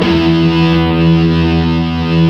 Index of /90_sSampleCDs/Roland L-CD701/GTR_Distorted 1/GTR_Power Chords
GTR PWRCHR04.wav